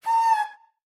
mob / ghast / affectionate_scream.ogg
affectionate_scream.ogg